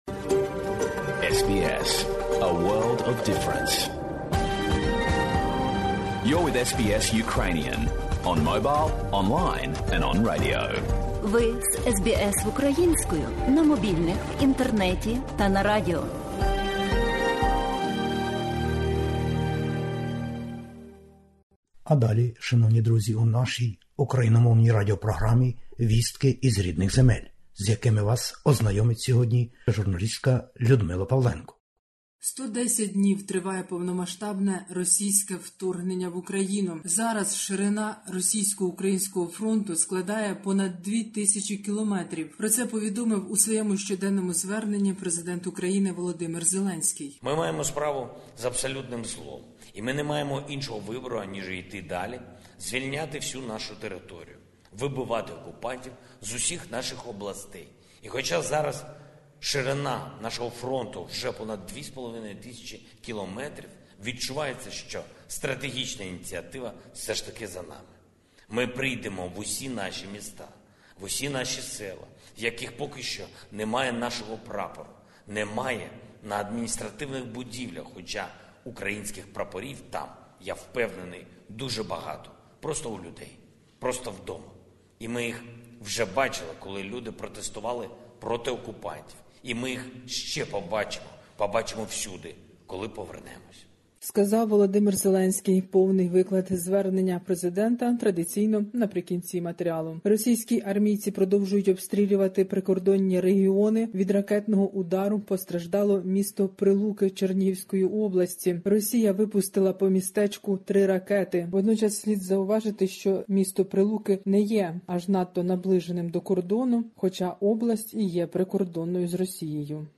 Добірка новин із героїчної України. Війна - фронт простягнувся на понад 2500 кілометрів.